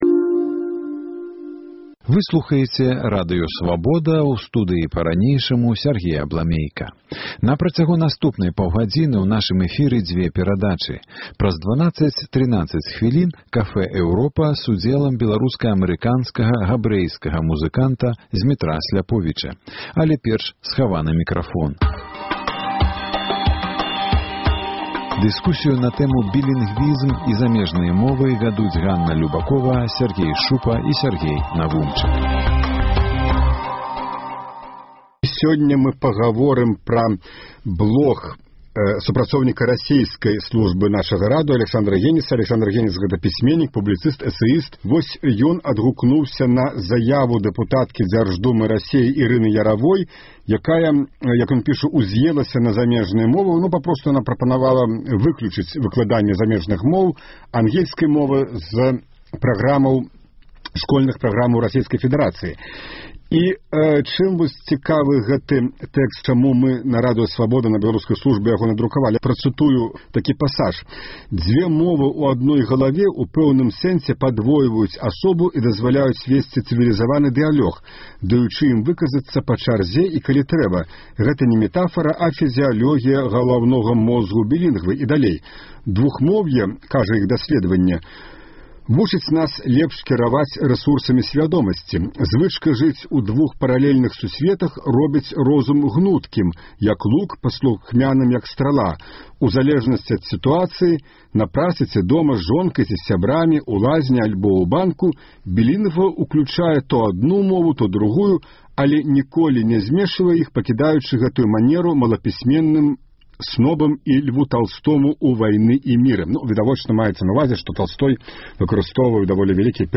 У дыскусіі з схаваным мікрафонам